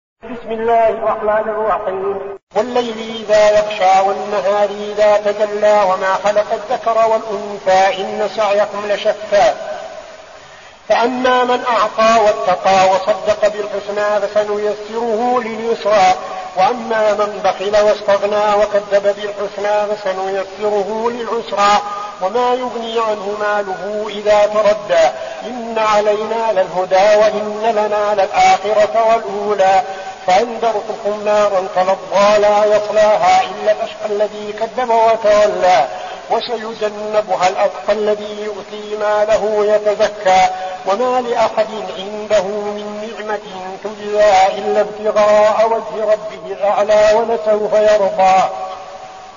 المكان: المسجد النبوي الشيخ: فضيلة الشيخ عبدالعزيز بن صالح فضيلة الشيخ عبدالعزيز بن صالح الليل